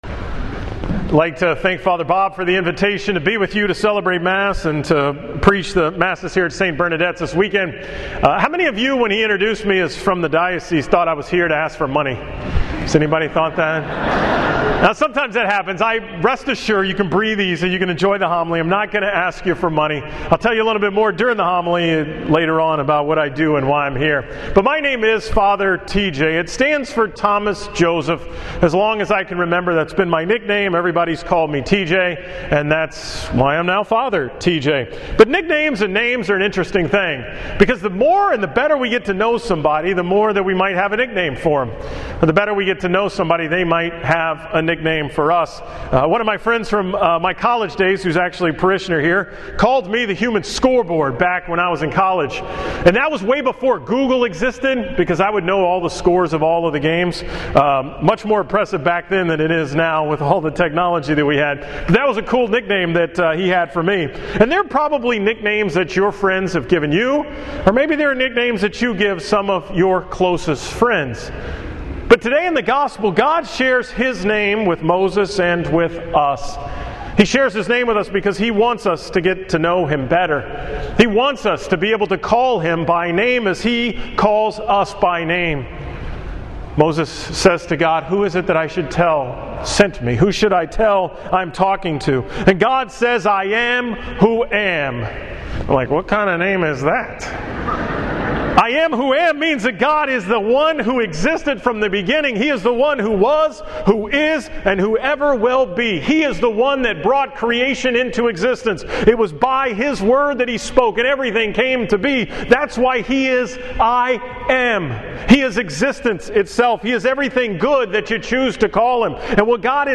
From the 11 am Mass at St. Bernadette's on February 28, 2016